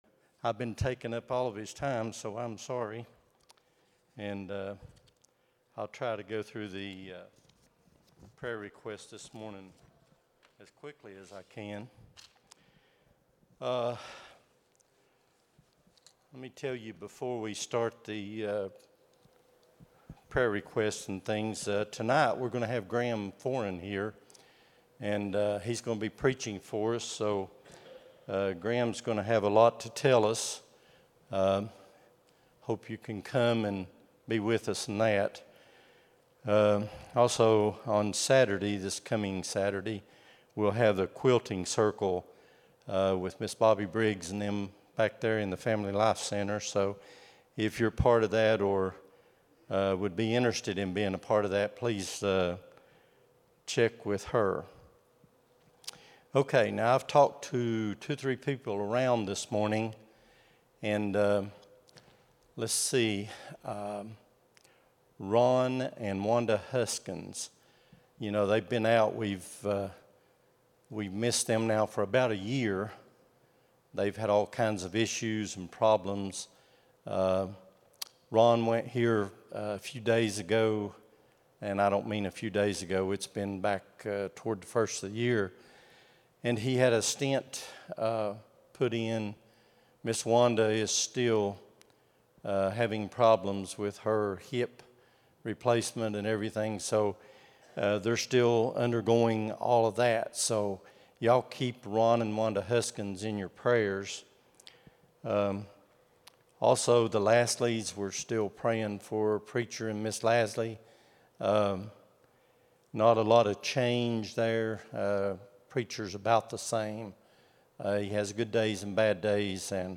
02-09-25 Sunday School | Buffalo Ridge Baptist Church